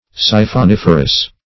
Search Result for " siphoniferous" : The Collaborative International Dictionary of English v.0.48: Siphoniferous \Si"phon*if"er*ous\, a. [Siphon + -ferous.]
siphoniferous.mp3